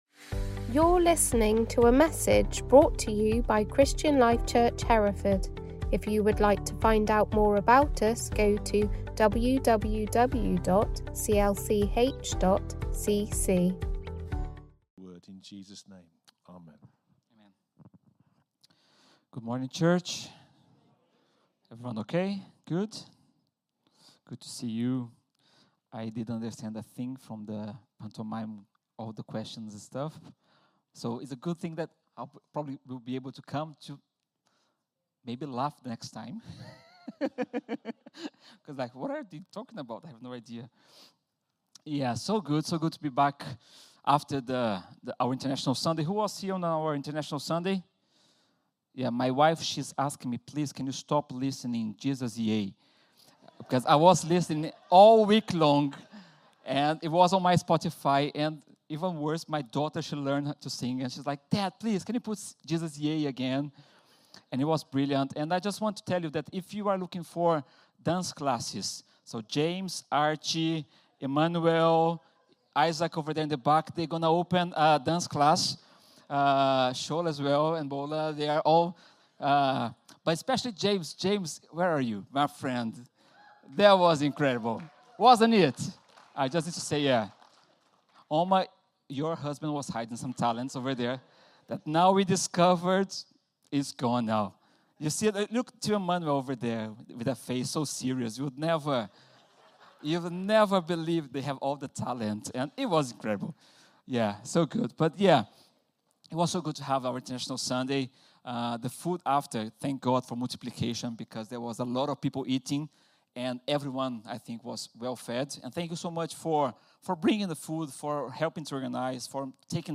Location: Hereford Sunday